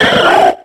Cri de Phogleur dans Pokémon X et Y.